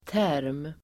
Ladda ner uttalet
Uttal: [tär:m]